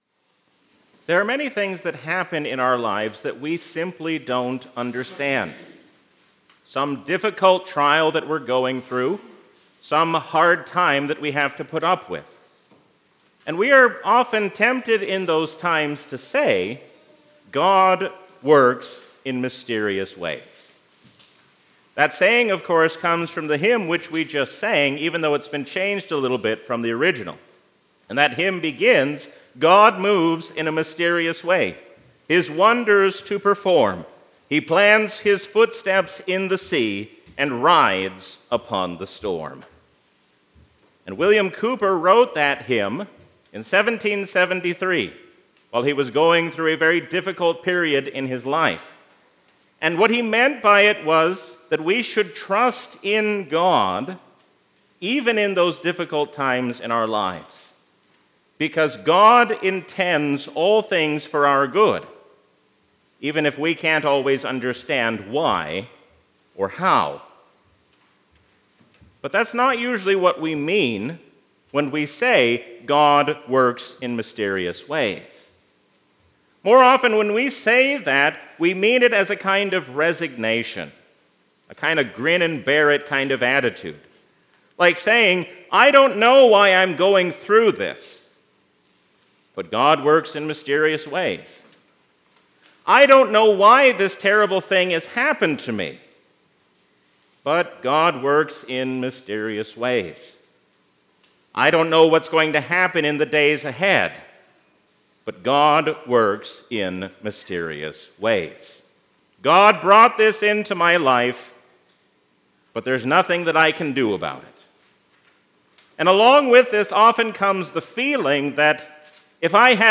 A sermon from the season "Easter 2023."